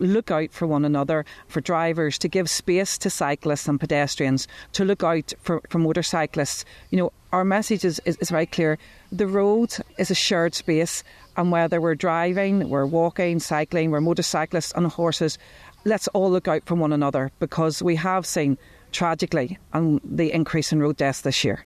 Assistant Commissioner for Roads Policing, Paula Hilman, says simple steps save lives: